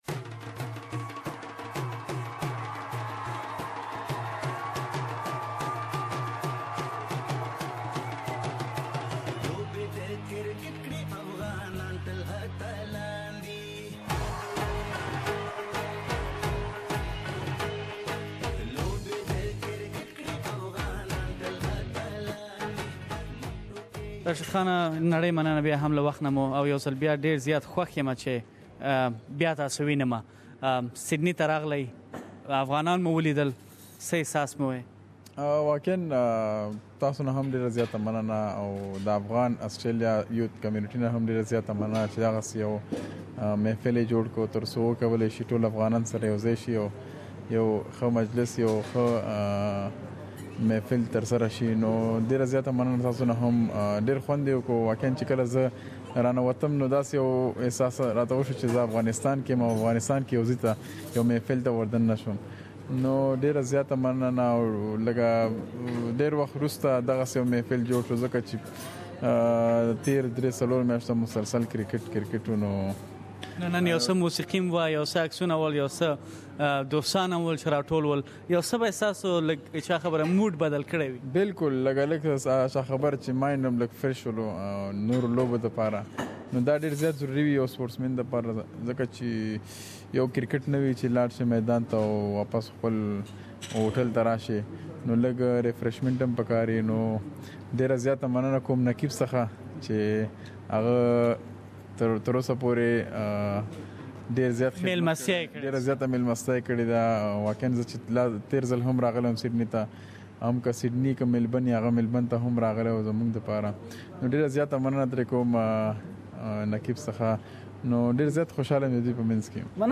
SBS Pashto program has interviewed the legend Rashid Khan, and you will hear a lot more from him. Please listen to the full interview here.